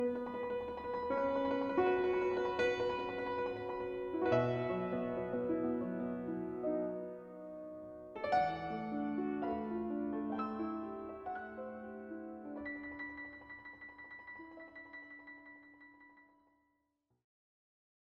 这些专集想最大限度的接近MJ的原始作品，为独奏音乐会的大钢琴编制。